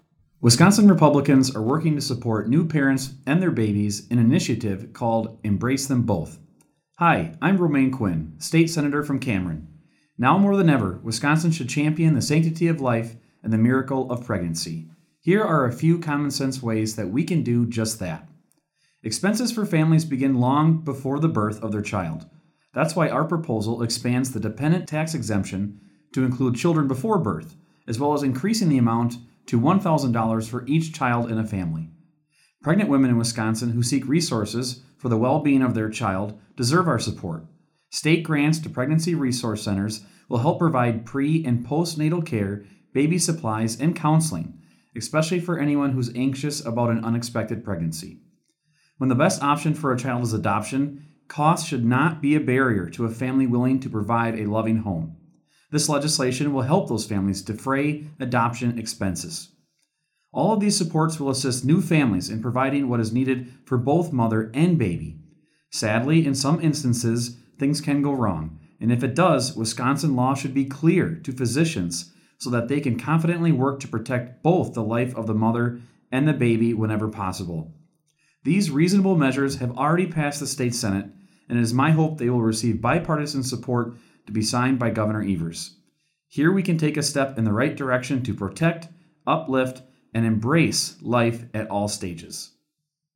Weekly GOP radio address: Life is precious, embrace them both